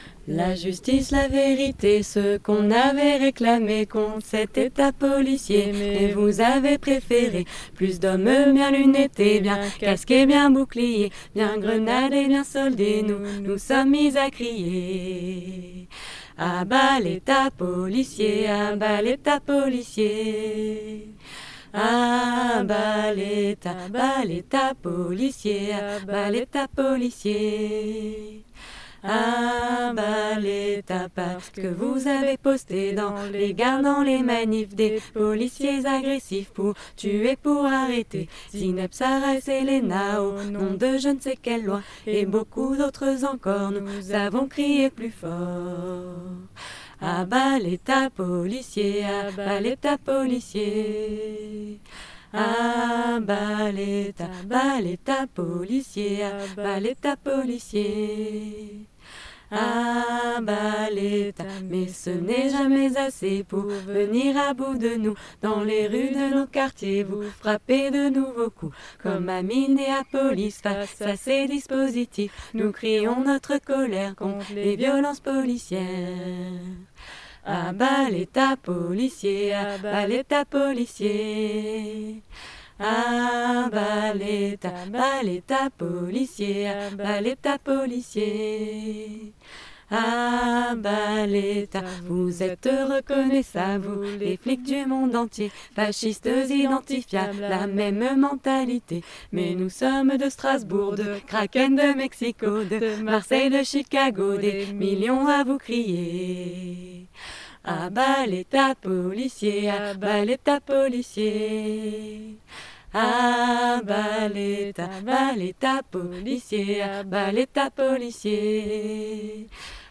Choeur de la Mascarade - a-bas-l-etat-voix-lead.mp3